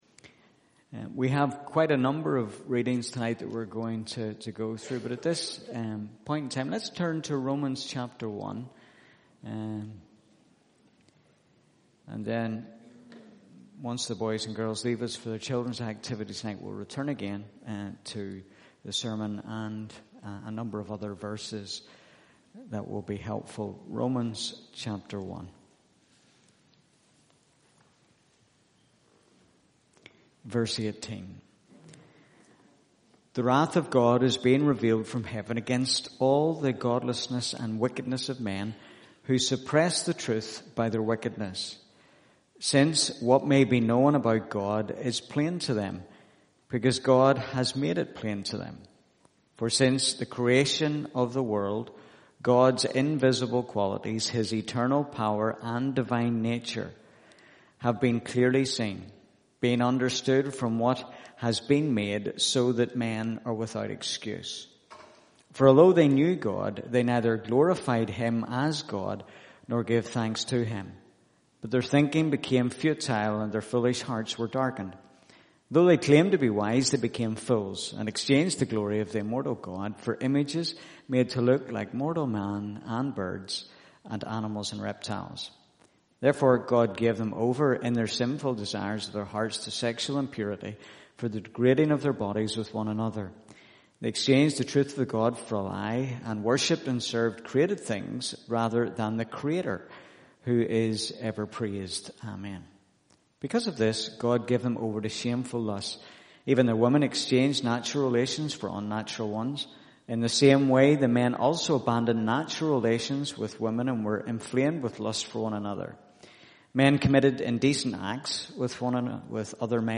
Service Type: pm